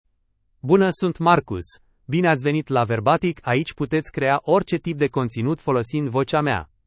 MaleRomanian (Romania)
MarcusMale Romanian AI voice
Voice sample
Listen to Marcus's male Romanian voice.
Marcus delivers clear pronunciation with authentic Romania Romanian intonation, making your content sound professionally produced.